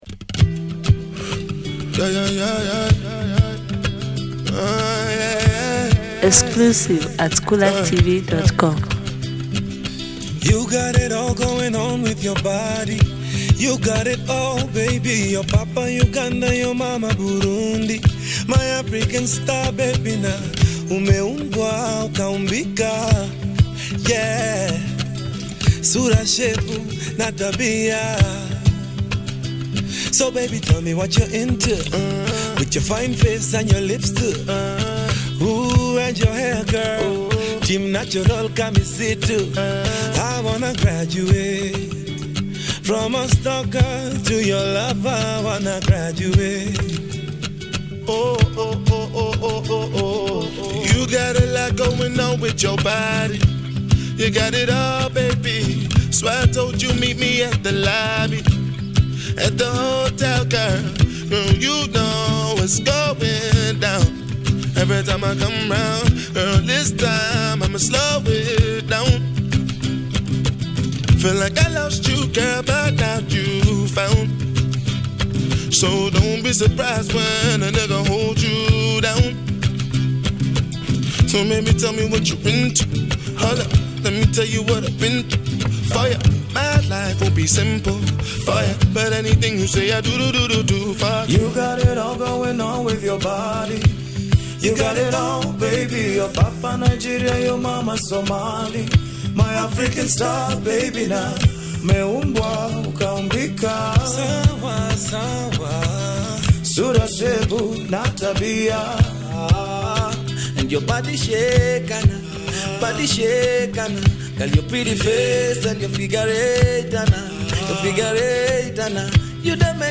Kenyan Afro Pop Band